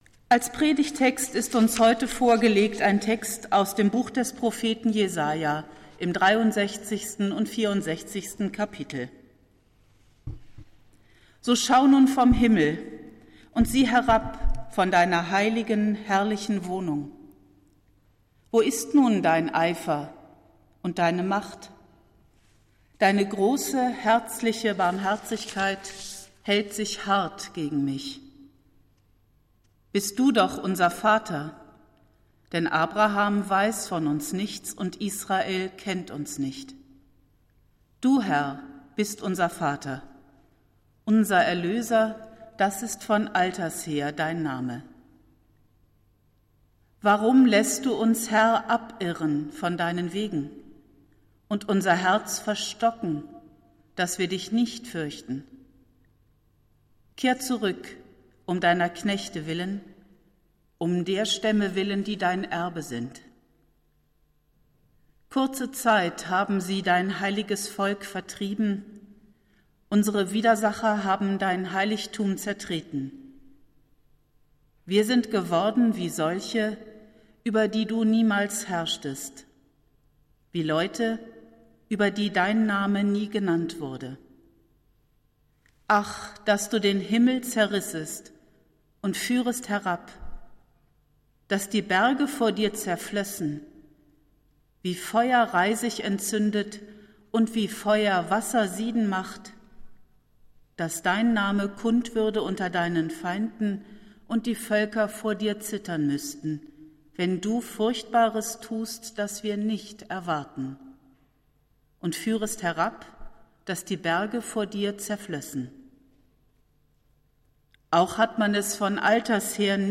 Predigt des Gottesdienstes aus der Zionskirche vom Sonntag, 05.12.2021
Wir haben uns daher in Absprache mit der Zionskirche entschlossen, die Predigten zum Nachhören anzubieten.